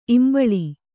♪ imbaḷi